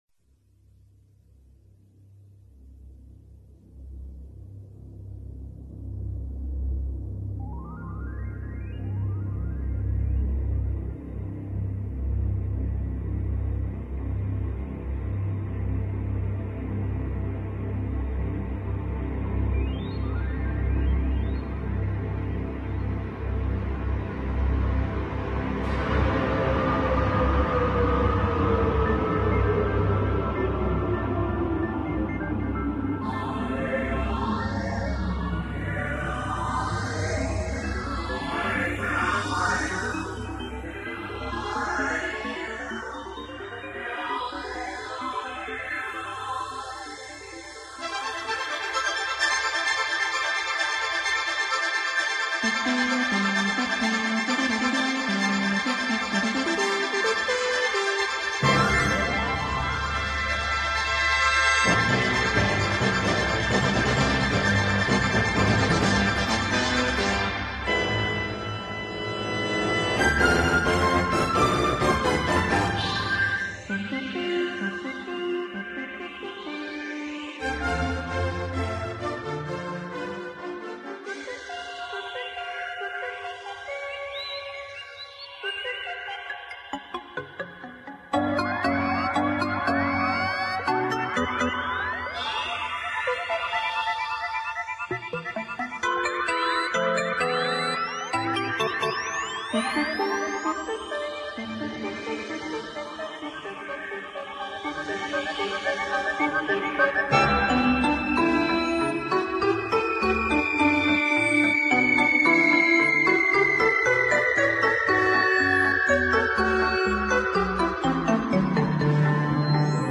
注：所有曲目均辗转取得，非自行压制，略有瑕疵，将就吧，想买CD买不到。